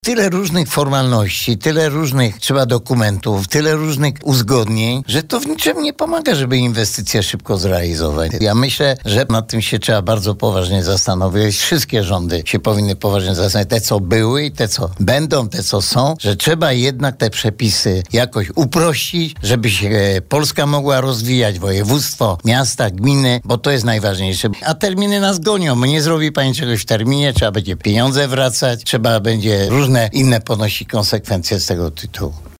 Mówi Antoni Szlagor.